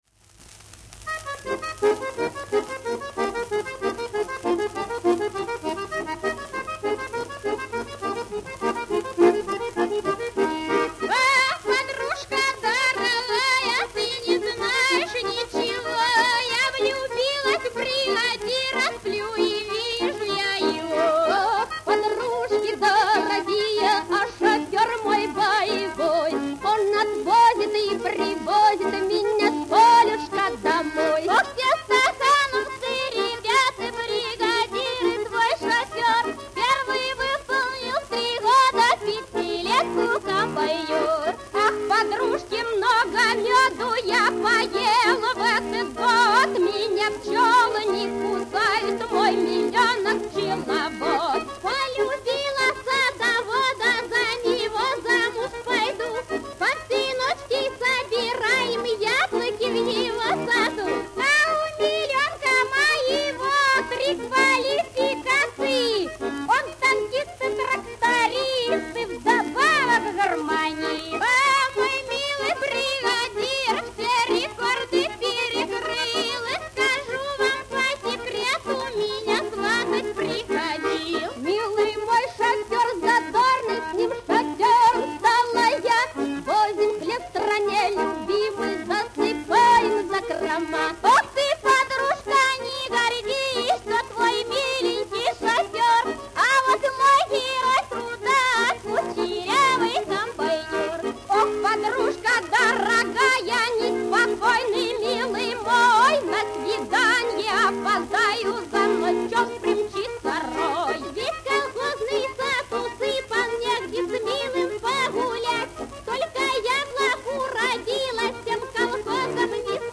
Колхозные частушки расхваливают передовиков-колхозников
Музыка: народная Слова